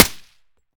Decay/sound/weapons/arccw_ud/m16/fire-sup-04.ogg at 5c1ce5c4e269838d7f7c7d5a2b98015d2ace9247
fire-sup-04.ogg